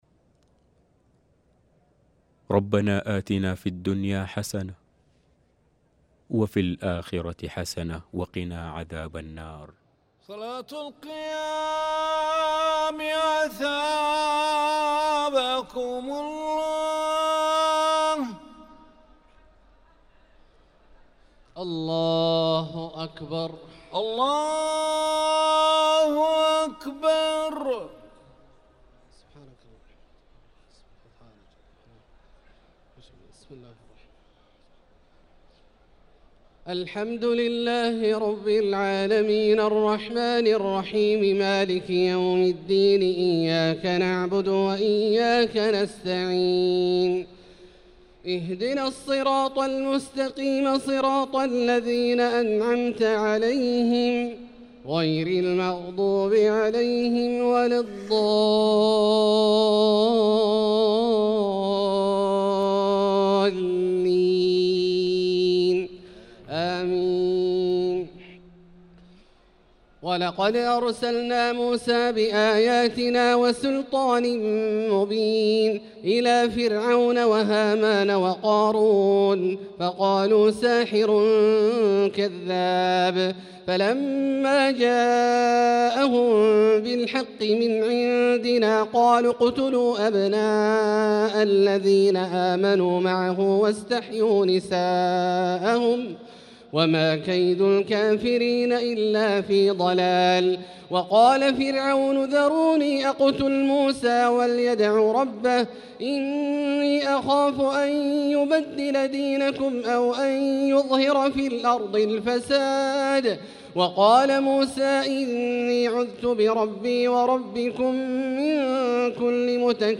صلاة التراويح ليلة 26 رمضان 1445 للقارئ عبدالله الجهني - الثلاث التسليمات الأولى صلاة التراويح